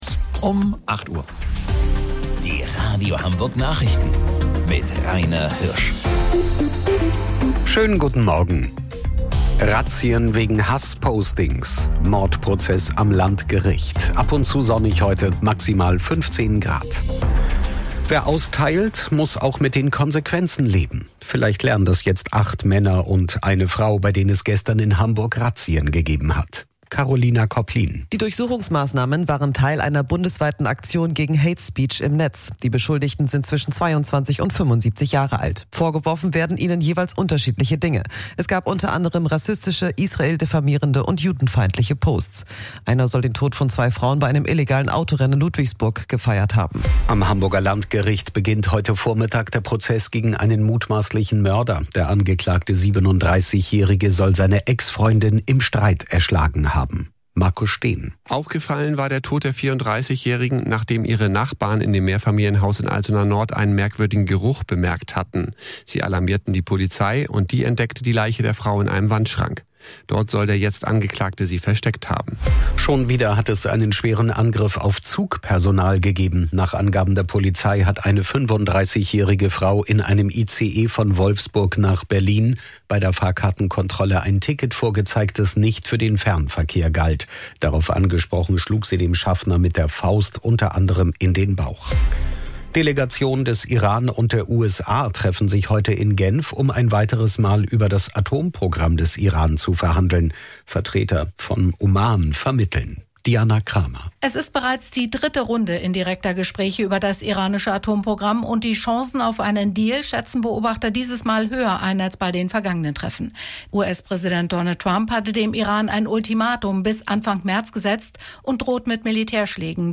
Radio Hamburg Nachrichten vom 26.02.2026 um 08 Uhr